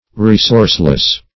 Search Result for " resourceless" : Wordnet 3.0 ADJECTIVE (1) 1. lacking or deficient in natural resources ; The Collaborative International Dictionary of English v.0.48: Resourceless \Re*source"less\, a. Destitute of resources.